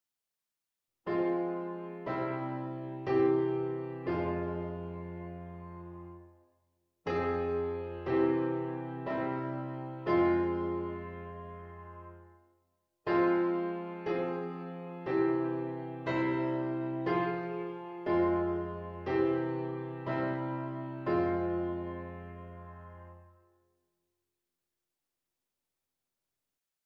d. figuurtjes met een of meer omleggingen (waarbij natuurlijk altijd sprongen ontstaan).
Harm_5_V_en_I_08_f-mineur_sound.mp3